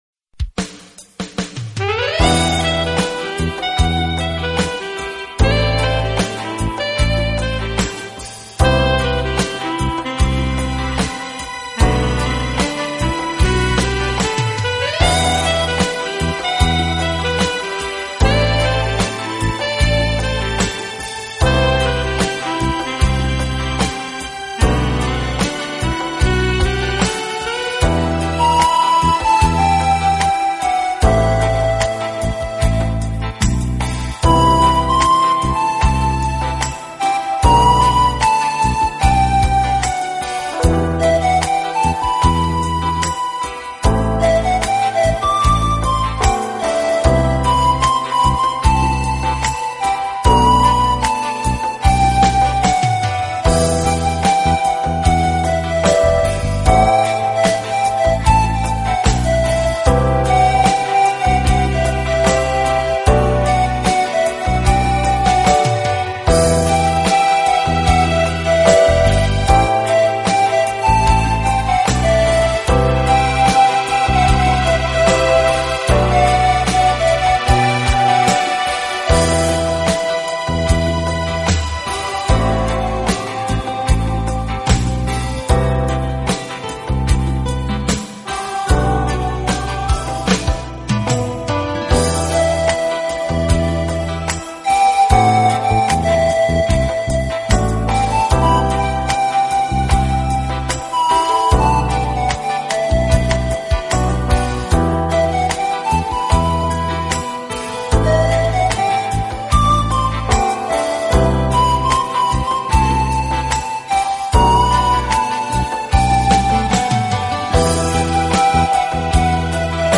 排箫专辑
版本: 排笛神童演繹古老蒼涼（純音樂）
排笛的声音略带瘖哑，有着一种苍凉的独特风味，十分迷人，因此特别